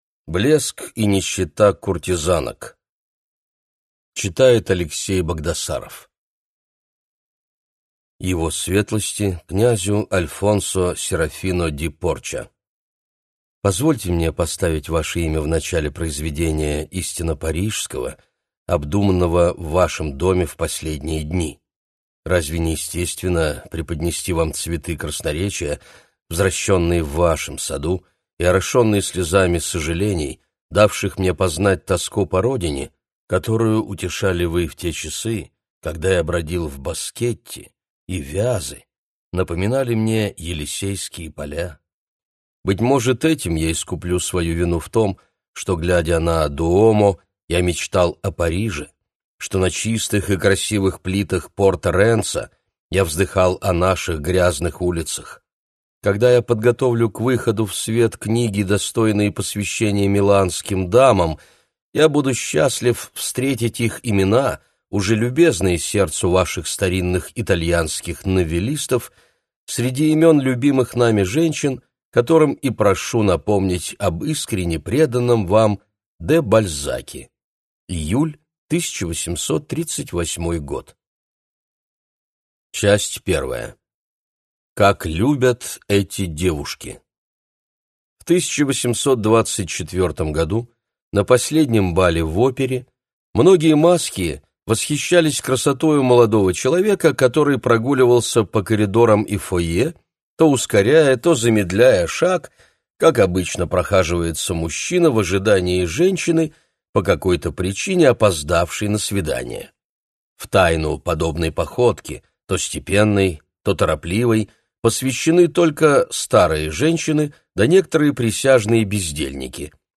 Аудиокнига Блеск и нищета куртизанок | Библиотека аудиокниг